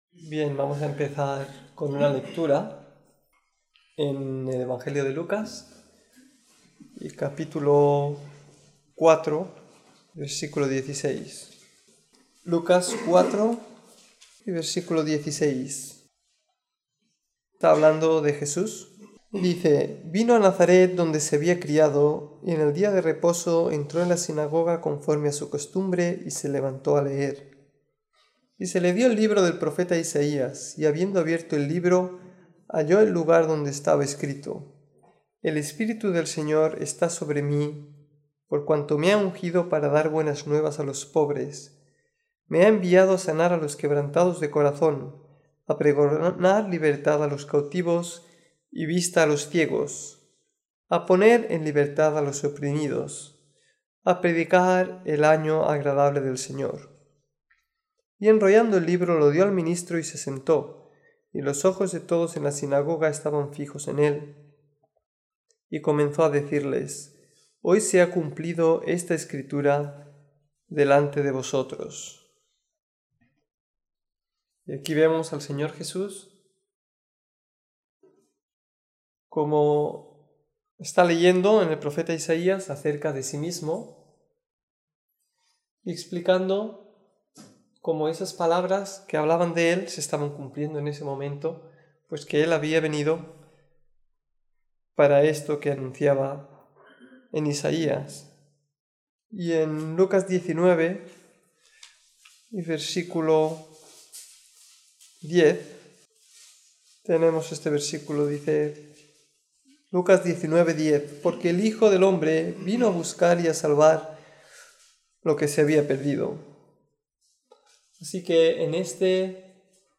Hoy en día está todo lleno de espíritus que atan a las personas y las esclavizan igual que entonces; - Lucas 13:10-17, Marcos 5.1-15, etc. -, sin embargo, la sociedad actual no quiere reconocer esto, o como mínimo, no con ese nombre. En esta predicación hablaremos sobre las esclavitudes y ataduras de los hombres.